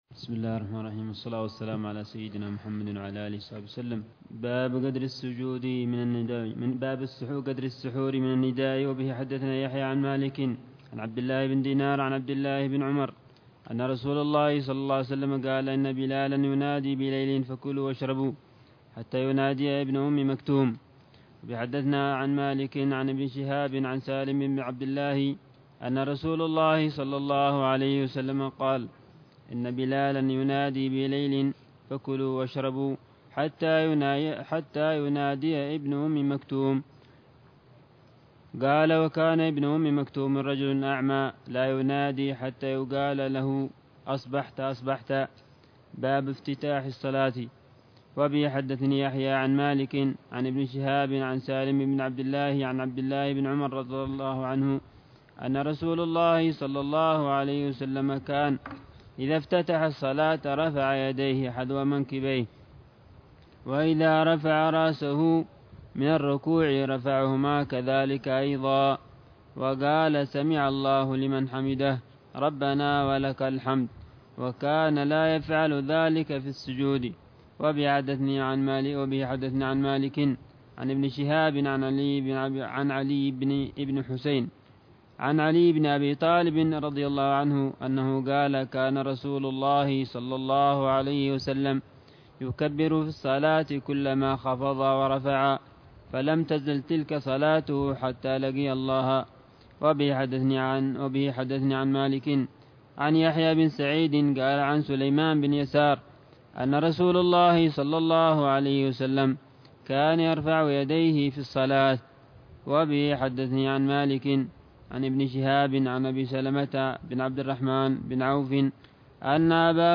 شرح الحبيب العلامة عمر بن محمد بن حفيظ على كتاب الموطأ لإمام دار الهجرة الإمام مالك بن أنس الأصبحي، برواية الإمام يحيى بن يحيى الليثي، كتاب ال